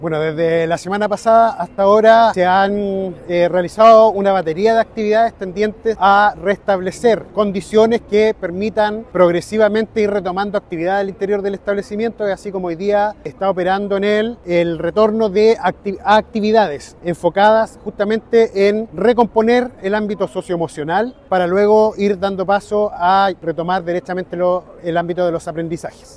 El Seremi de Educación de la región del Bío Bíoo, Carlos Benedetti, detalló que los estudiantes regresaron con un horario de 3 horas, el que incluyó actividades con apoyo socioemocional.
cuna-seremi-educacion.mp3